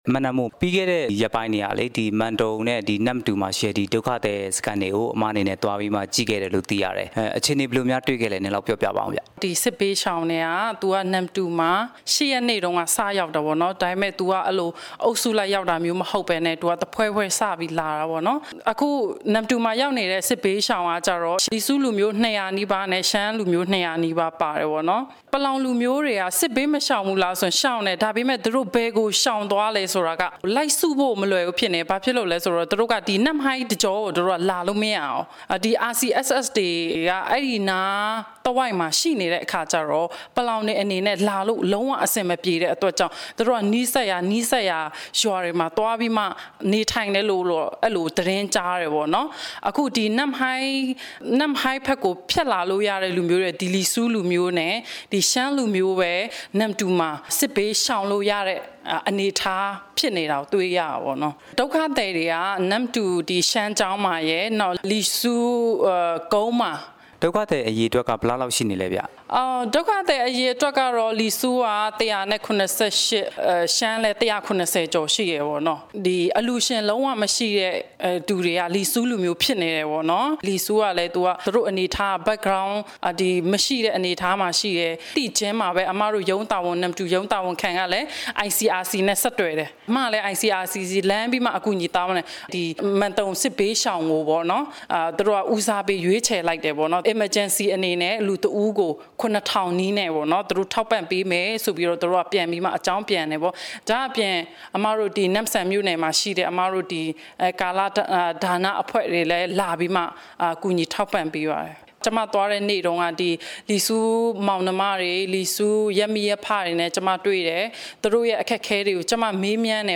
ပလောင်ဒုက္ခသည်အရေး တွေ့ဆုံမေးမြန်းချက်